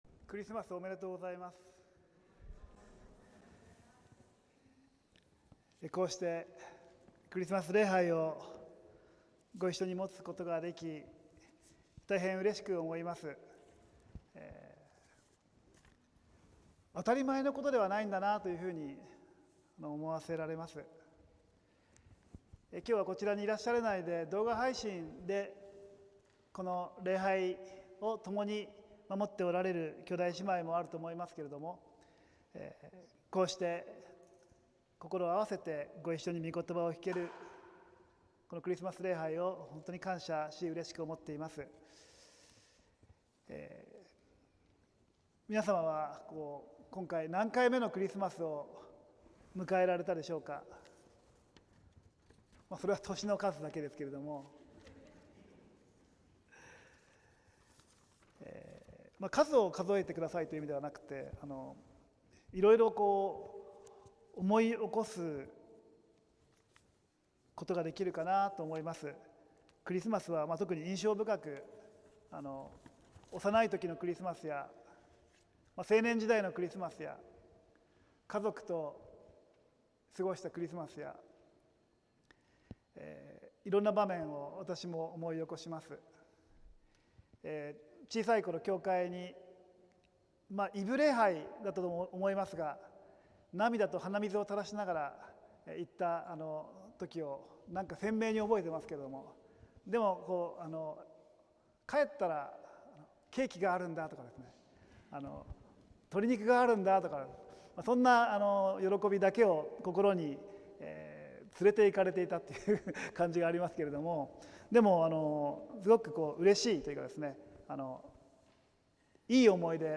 浦和福音自由教会(さいたま市浦和区)のクリスマス礼拝(2025年12月21日)「ことばが肉となった」(週報とライブ/動画/音声配信)